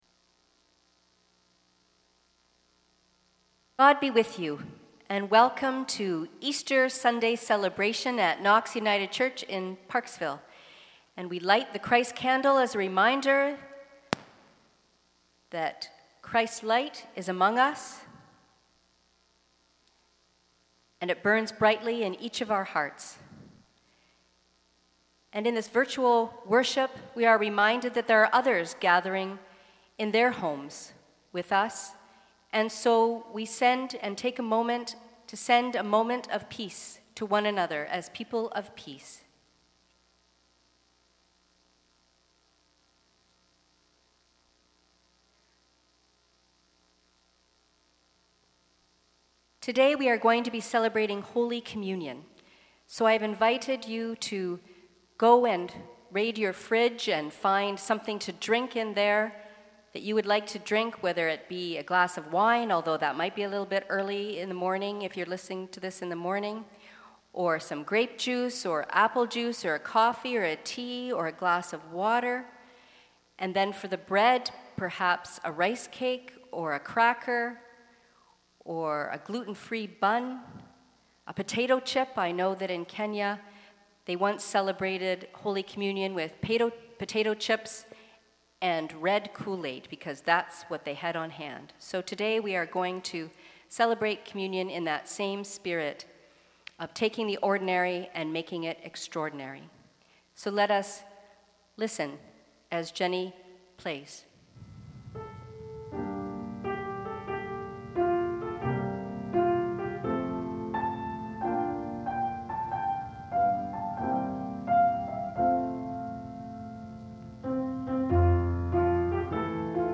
Sermons | Knox United Church